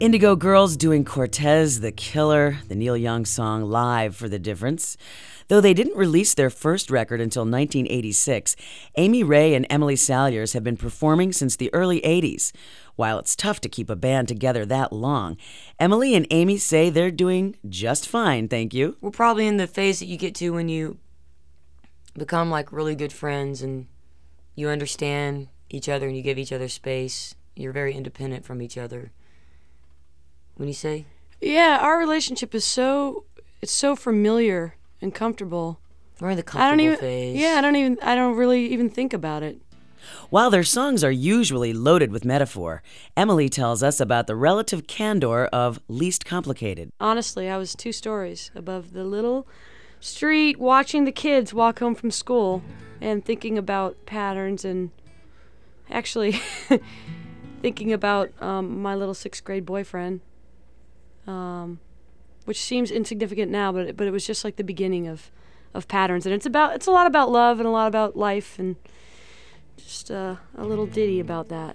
11. interview (1:11)